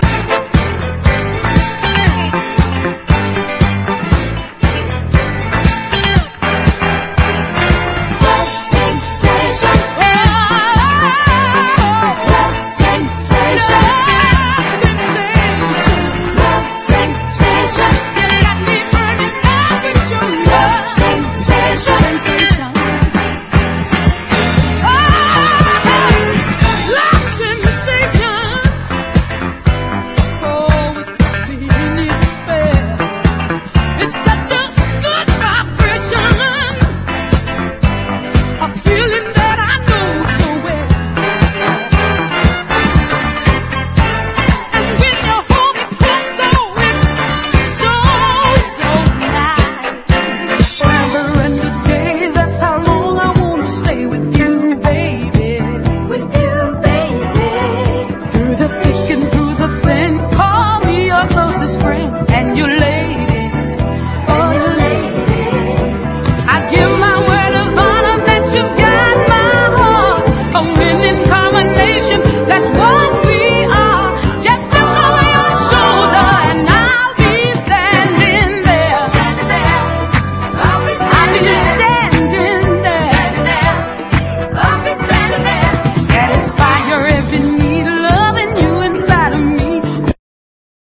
SOUL / SOUL / 70'S～ / DISCO